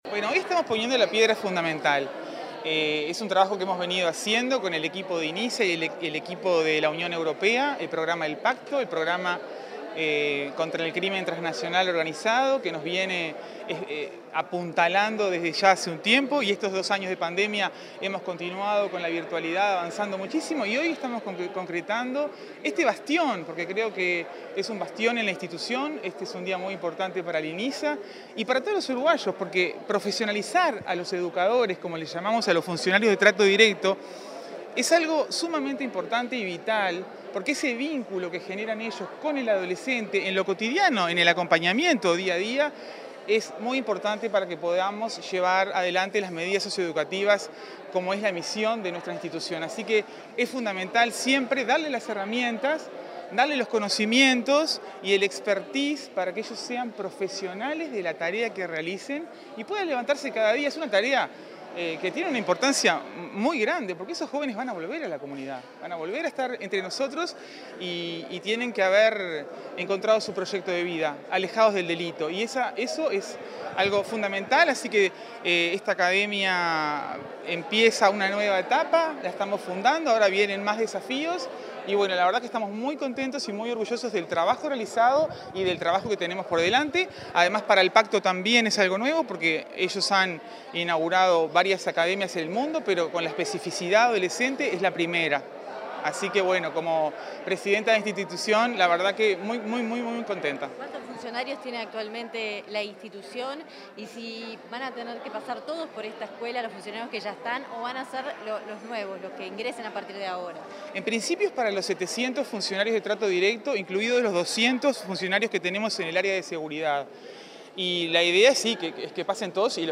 Declaraciones a la prensa de la presidenta de Inisa
La presidenta del Instituto Nacional de Inclusión Social Adolescente (Inisa), Rosanna de Olivera, dialogó con la prensa luego de participar del Primer